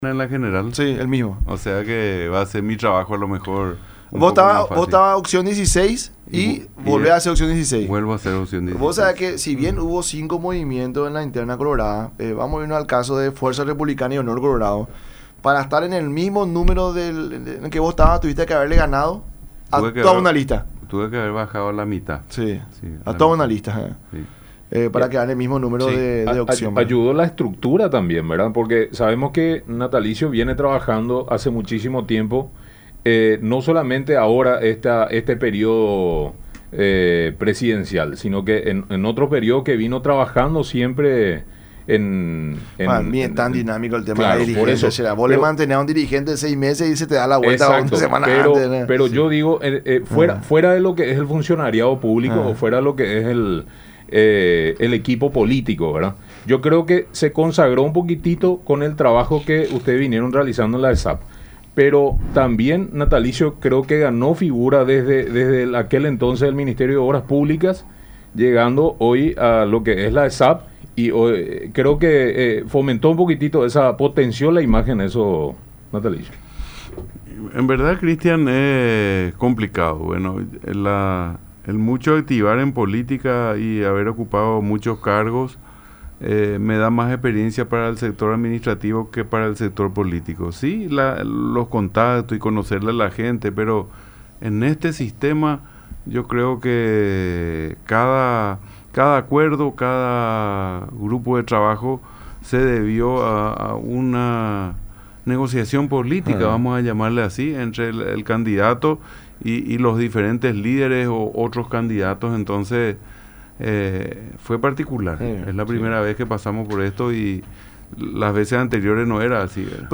Hay que gente que fue dueña de nuestra ilusión y esperanza durante años caminan ahora por la calle diciéndonos que ahora sí van a hacer, pero estuvo ya en el Congreso 20 o 30 años”, expuso en su visita a los estudios de Unión TV y radio La Unión durante el programa La Mañana De Unión.